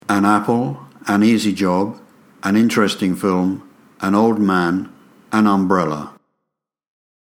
Decimos a + sonido consonante y an + sonido vocal